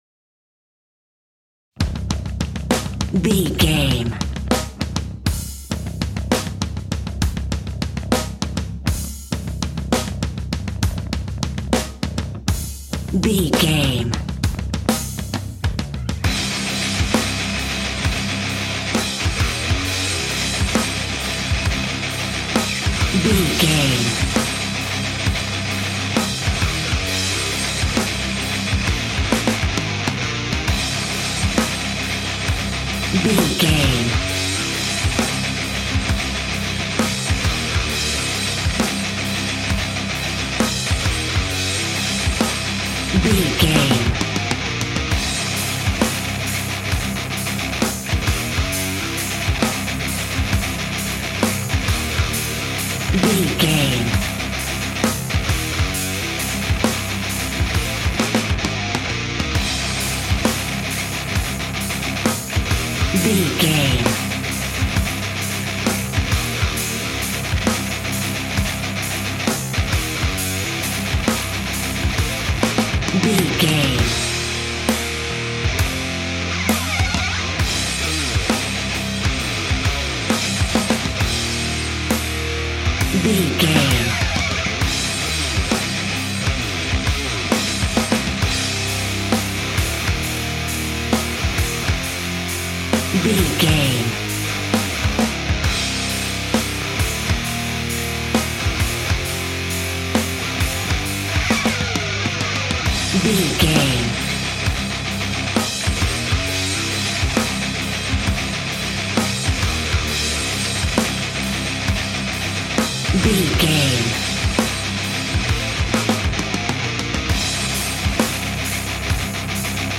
Epic / Action
Fast paced
Aeolian/Minor
hard rock
heavy metal
dirty rock
rock instrumentals
Heavy Metal Guitars
Metal Drums
Heavy Bass Guitars